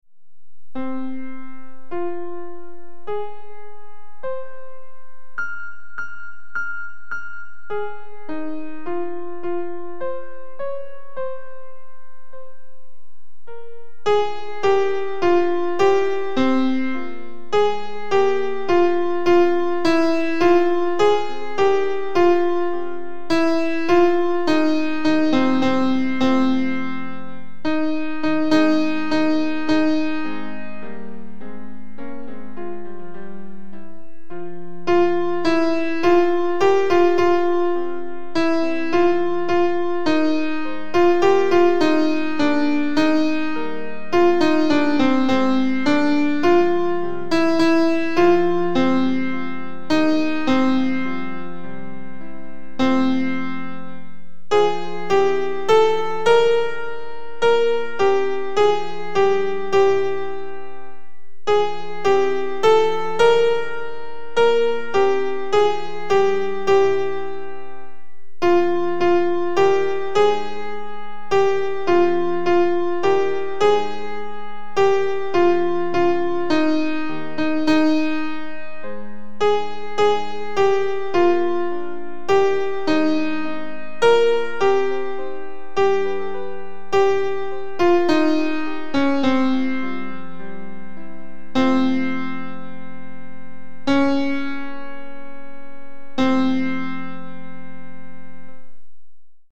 Sound – Alto.mp3
Victoria_Alto.mp3